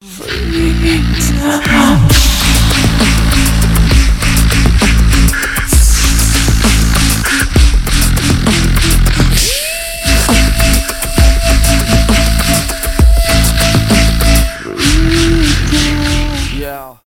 дабстеп
битбокс , рэп